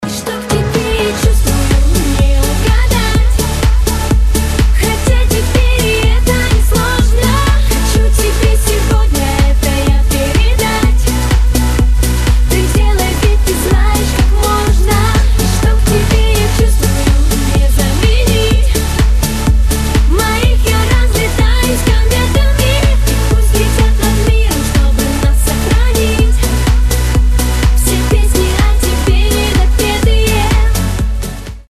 • Качество: 256, Stereo
поп
dance
vocal